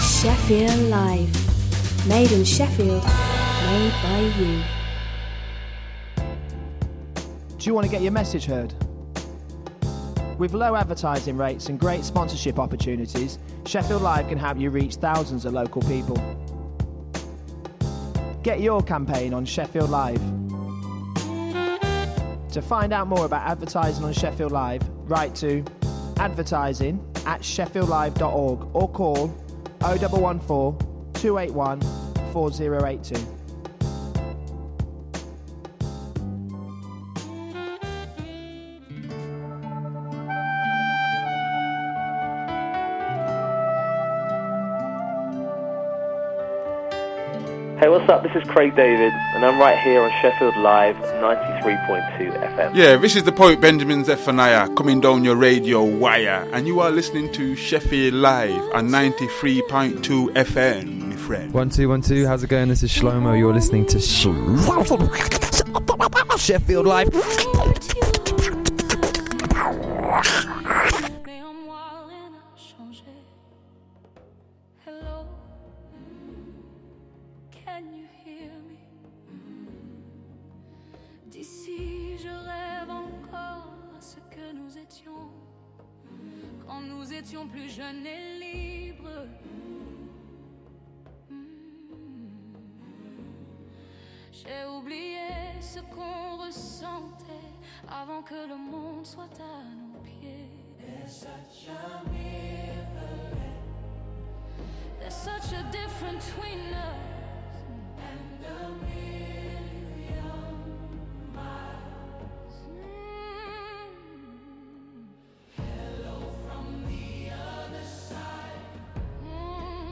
Talking Balls is a tongue in cheek sports chat show looking at the latest sports news and stories, with both interviews, previews and competitions